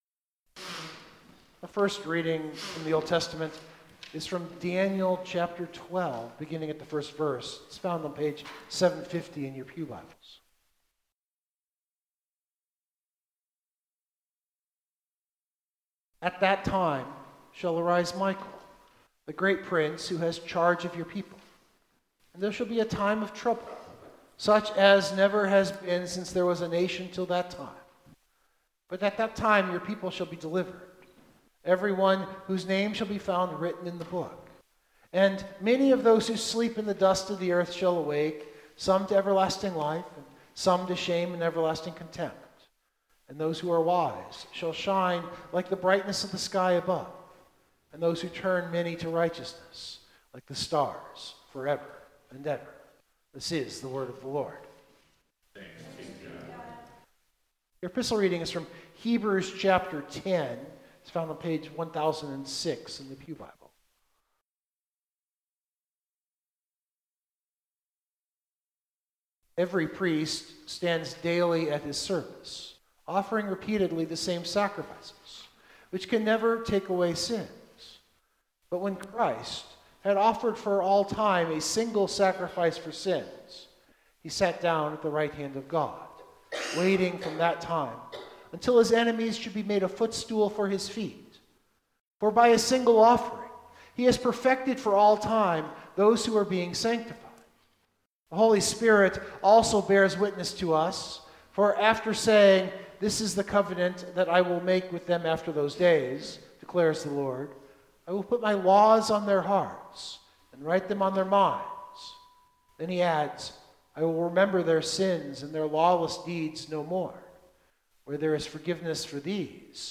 Biblical Text: Mark 13:14-37 Full Sermon Draft
The last Sunday of the Church Year.